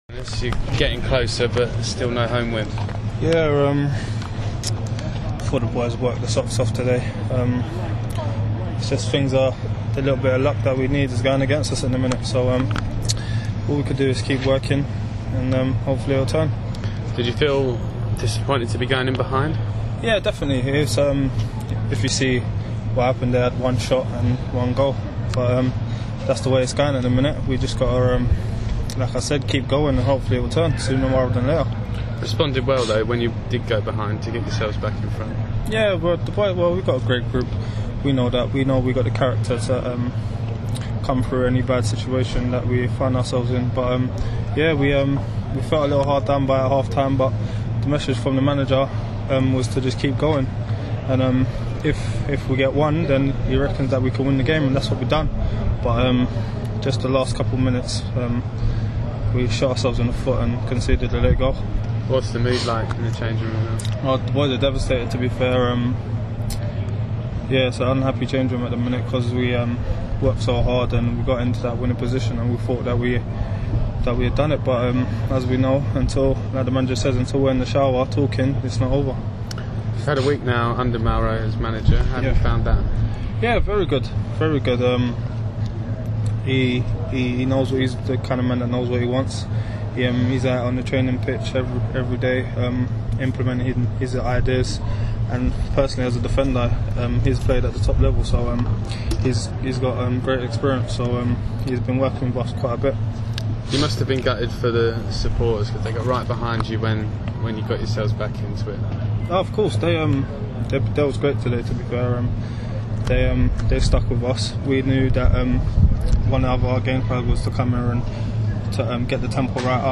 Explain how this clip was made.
speaking after Orient's draw with Coventry